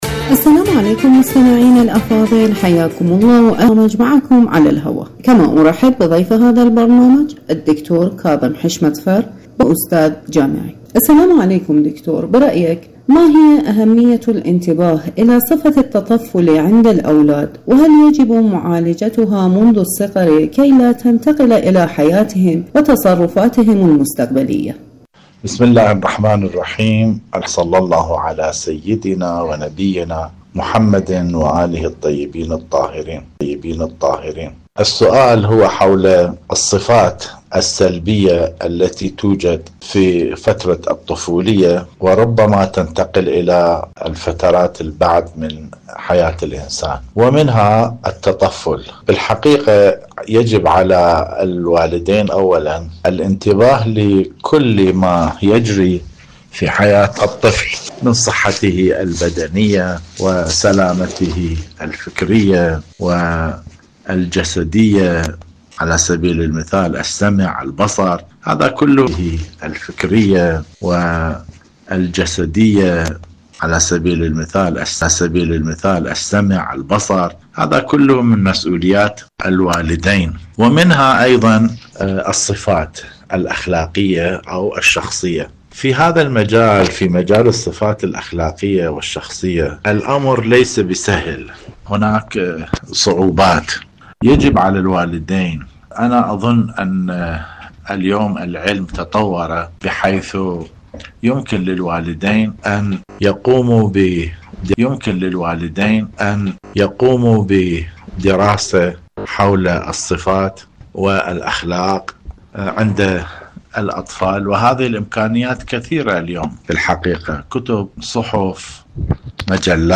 إذاعة طهران- معكم على الهواء: مقابلة إذاعية